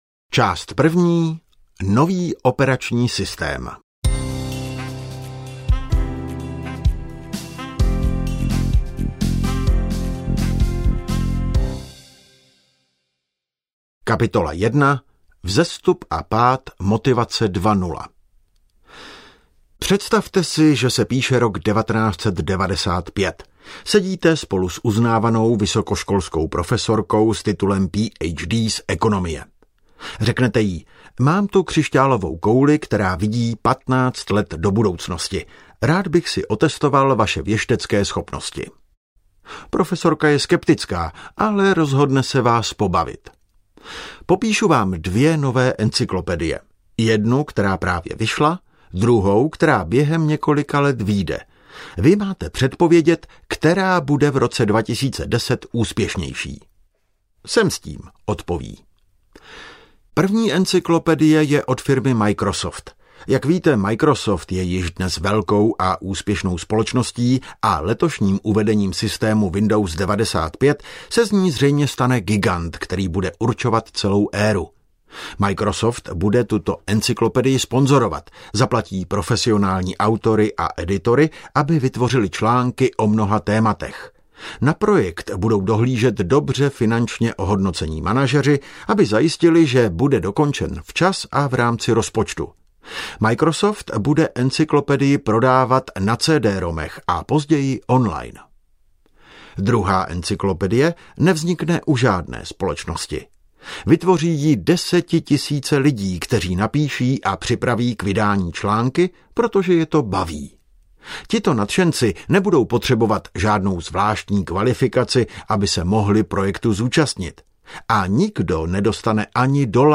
Drive / Pohon audiokniha
Ukázka z knihy